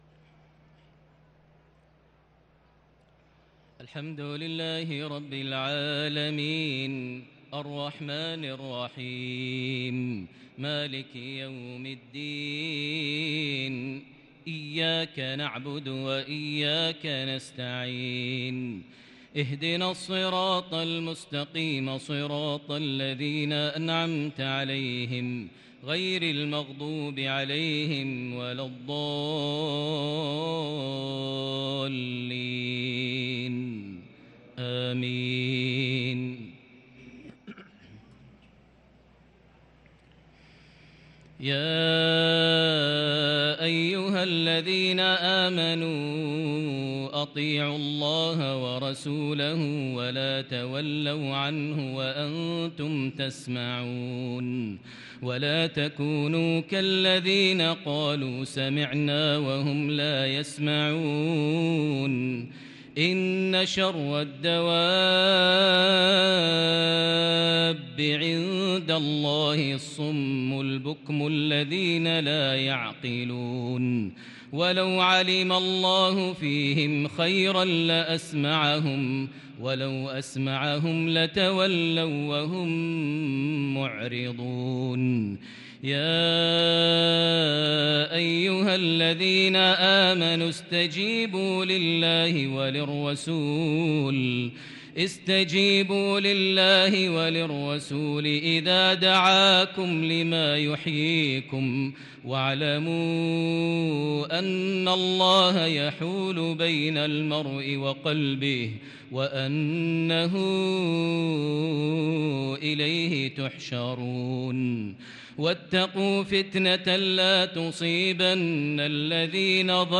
صلاة العشاء للقارئ ماهر المعيقلي 12 ربيع الأول 1444 هـ
تِلَاوَات الْحَرَمَيْن .